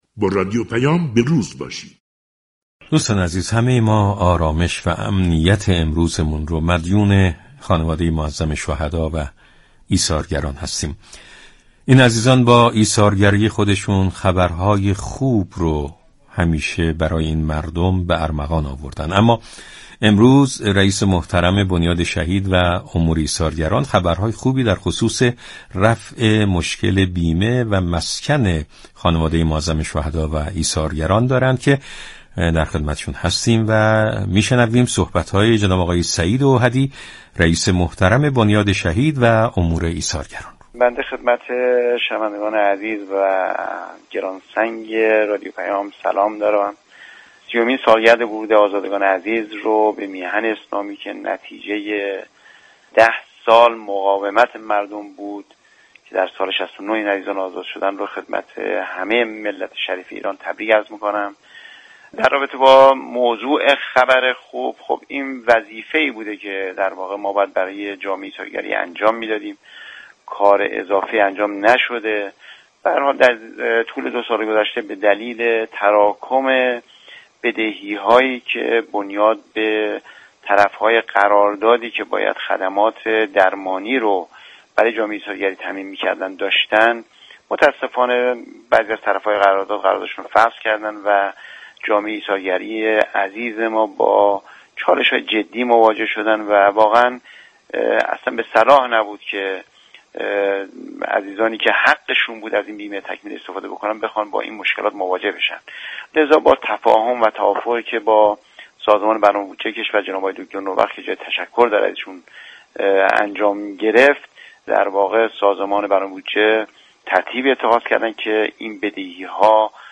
اوحدی در گفتگو با رادیو پیام ، از حل مشكل بیمه تكمیلی جامعه ایثارگران خبر داد و همچنین در حوزه مسكن نیز افزود ،تا پایان سال تامین زمین برای ٣٠ هزار واحد مسكونی ایثارگران در سراسر كشور محقق خواهد شد .